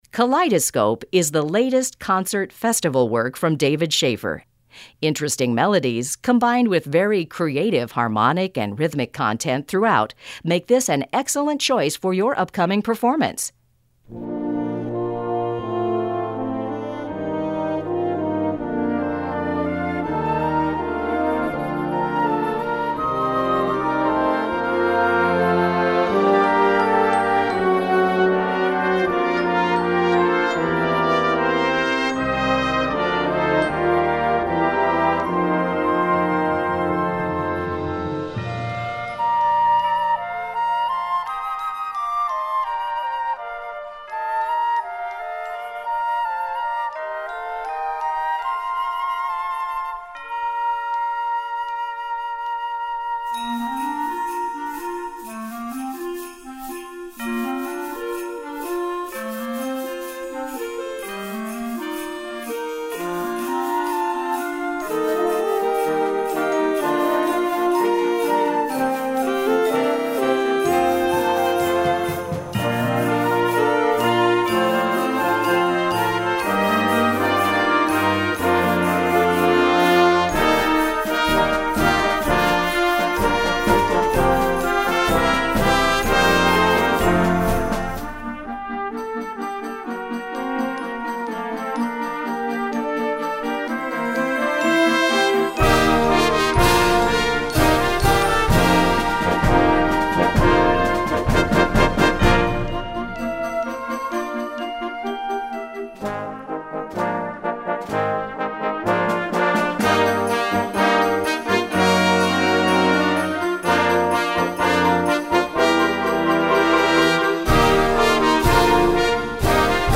Gattung: Developing Bands
2:32 Minuten Besetzung: Blasorchester Zu hören auf